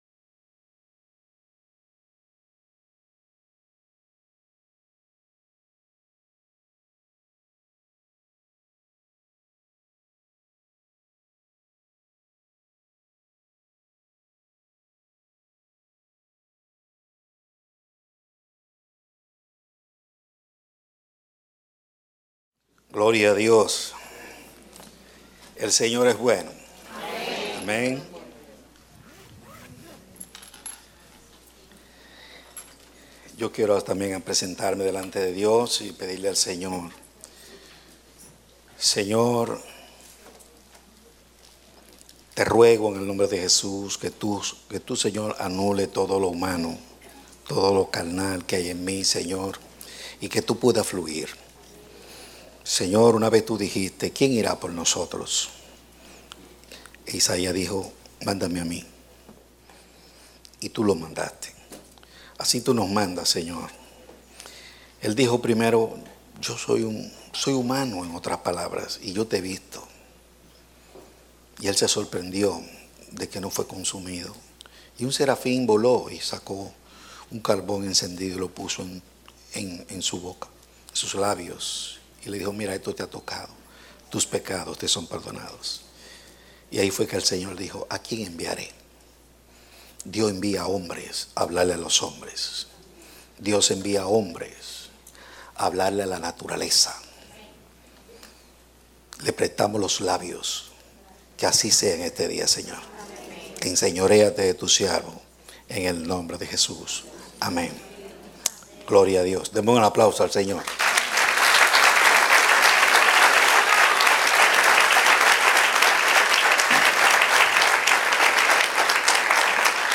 Predicado Domingo 28 de Agosto, 2016